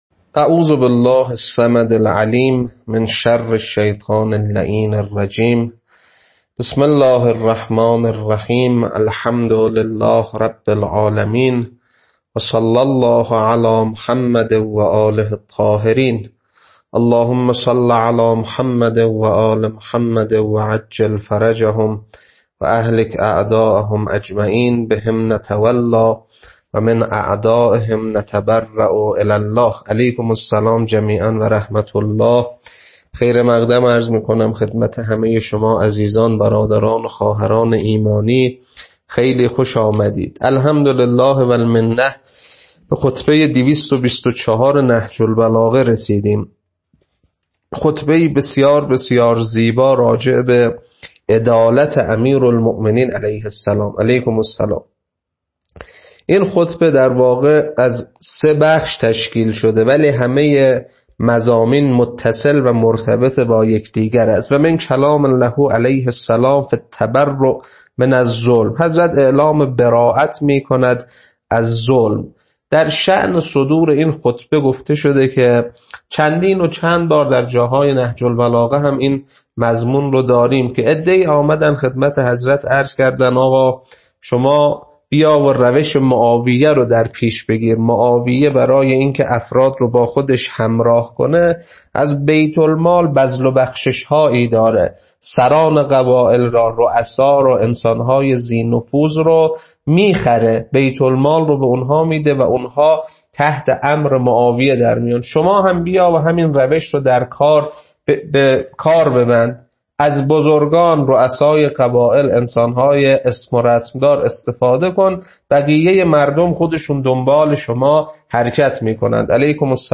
خطبه 224.mp3
خطبه-224.mp3